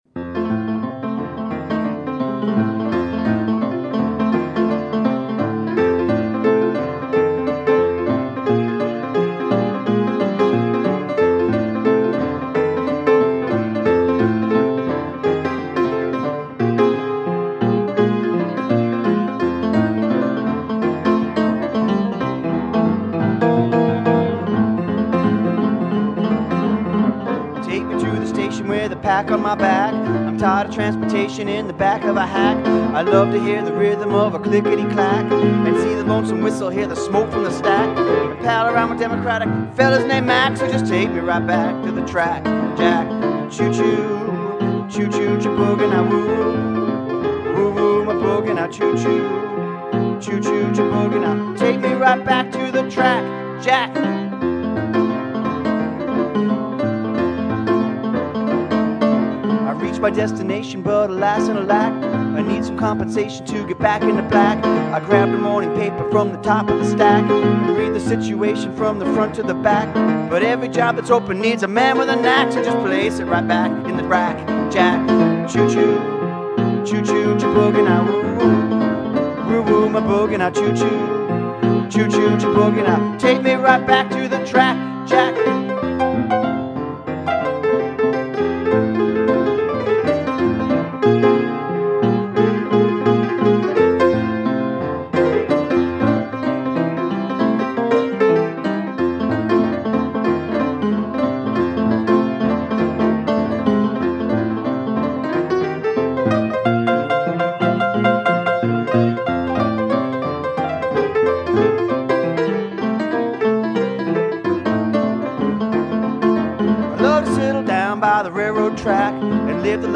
Piano
inside the 'Ark', in New Orleans,
a warehouse art and music club.